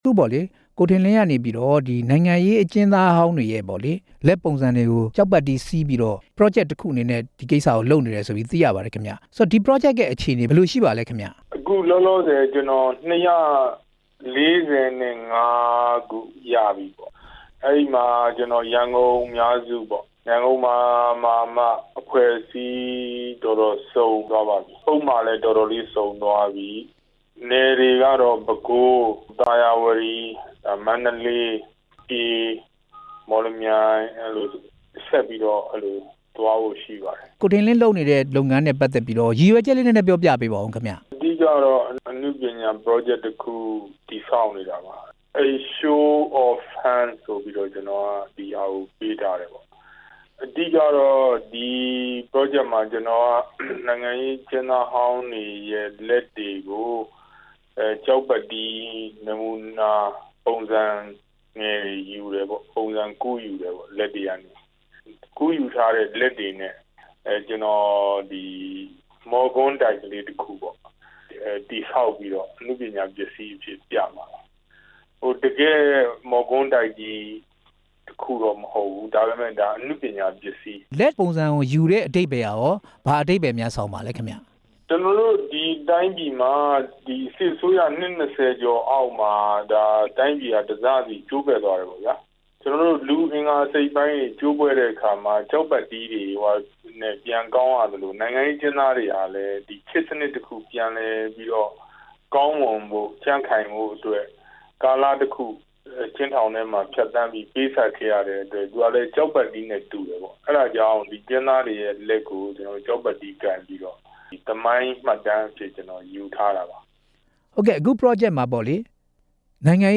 ဆက်သွယ်မေးမြန်းခဲ့ပါတယ်။